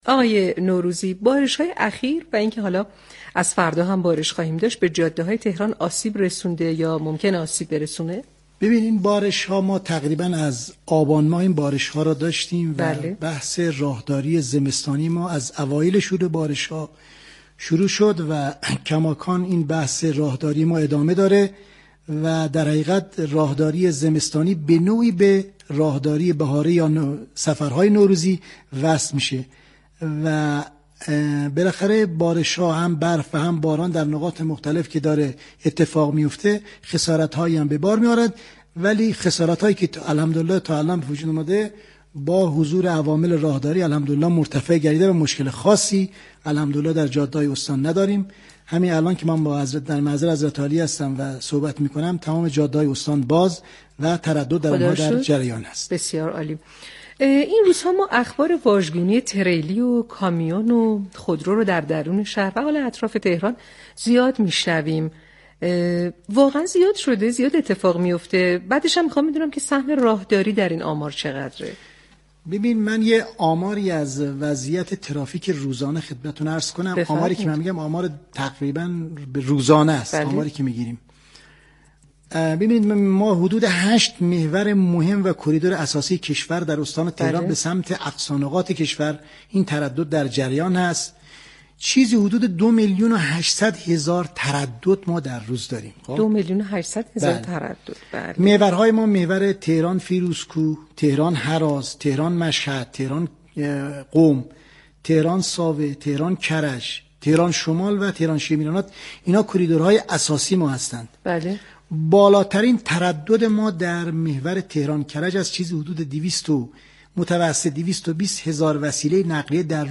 به گزارش پایگاه اطلاع رسانی رادیو تهران، مدیركل راهداری استان تهران حیدر نوروزی مدیركل راهداری استان تهران با حضور در استودیو پخش زنده رادیو تهران درباره تاثیر بارش‌های زمستانی بر راه‌های استان تهران به برنامه پل مدیریت گفت: بارش‌ها تقریبا از آبانماه و راهداری زمستانی هم از اوایل شروع بارش ها آغاز شد و به هم اكنون به سفرهای نوروزی وصل شده است.